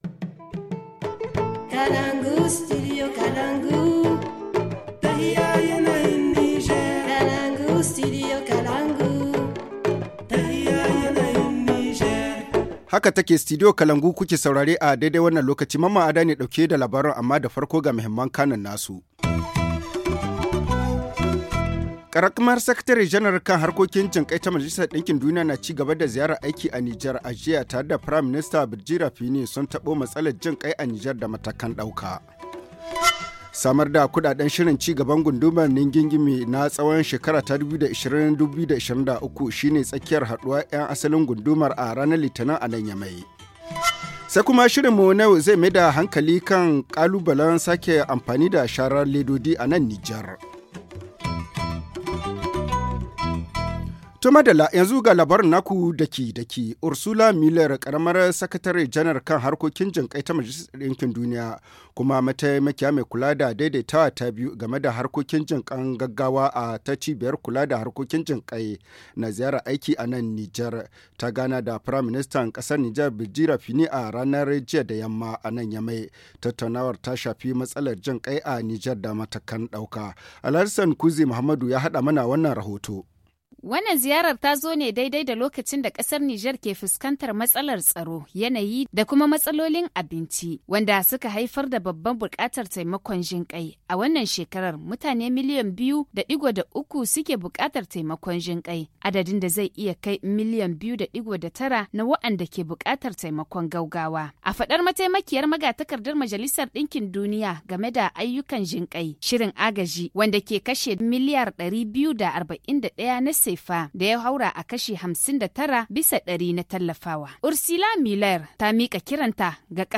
Le journal du 11 decembre 2019 - Studio Kalangou - Au rythme du Niger